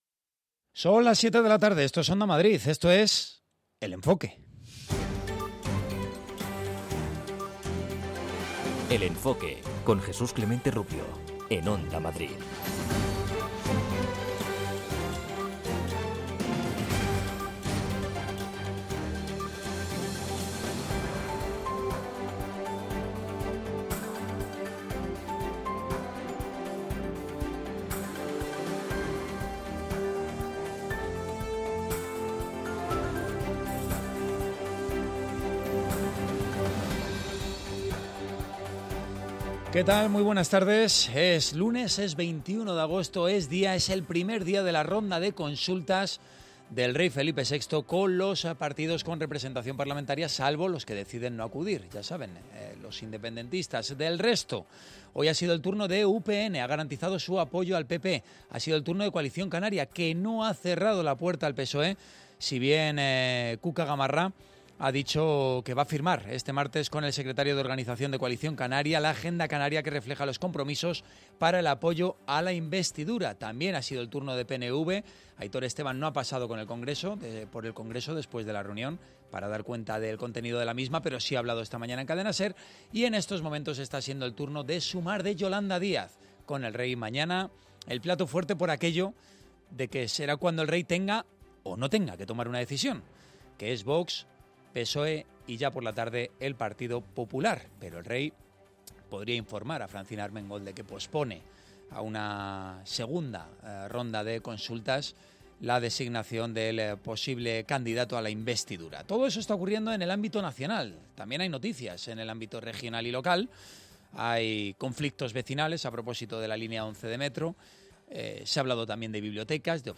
Cada tarde desde las 19 horas Félix Madero da una vuelta a la actualidad, para contarte lo que ha pasado desde todos los puntos de vista. La información reposada, el análisis, y las voces del día constituyen el eje central de este programa, con la vista puesta en lo que pasará al día siguiente.